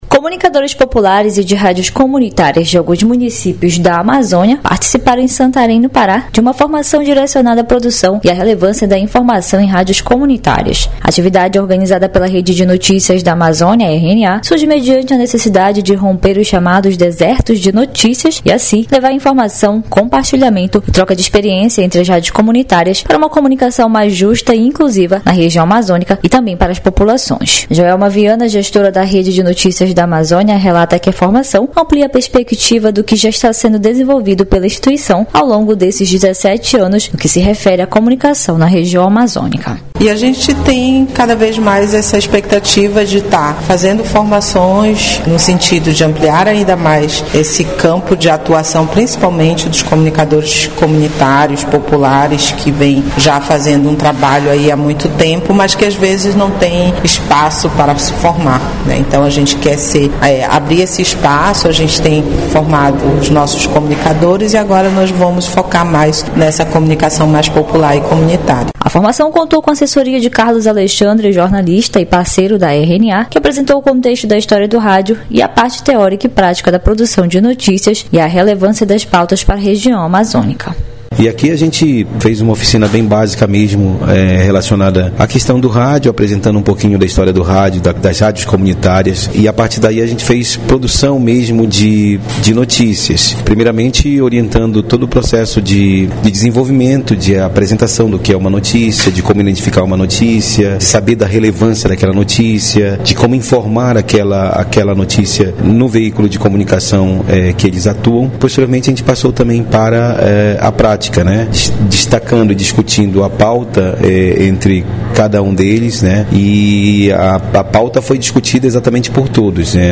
A reportagem